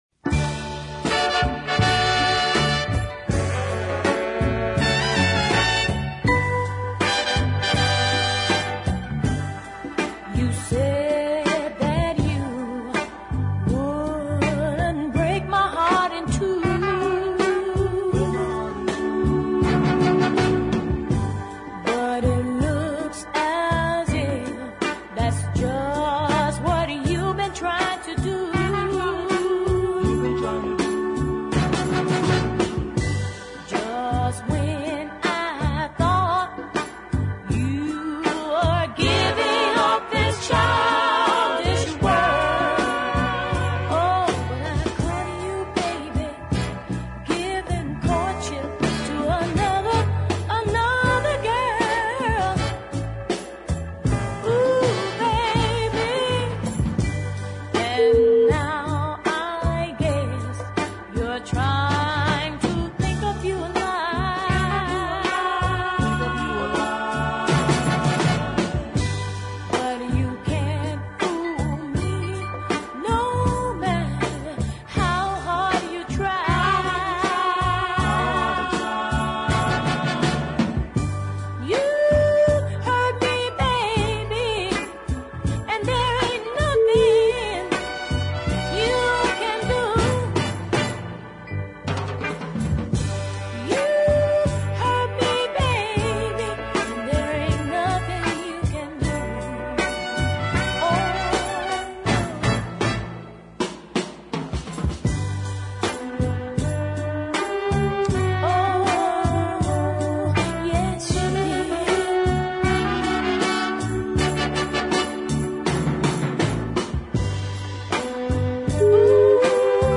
little girl vulnerability